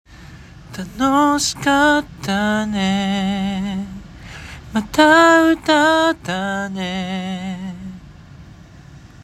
④ビブラート
例えば「あーーー」と伸ばした時に音程が上下に波打つテクニックです。